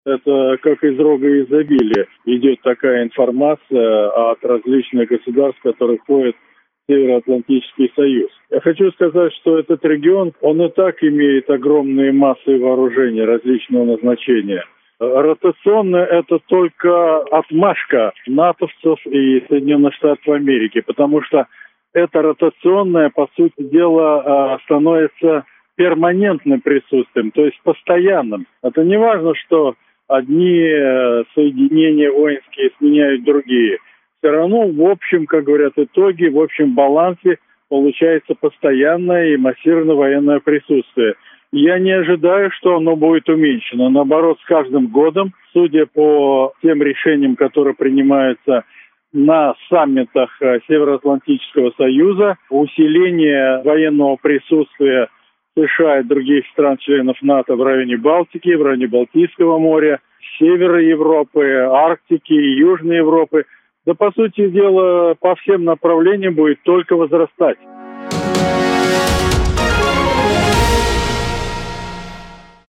В интервью Sputnik Латвия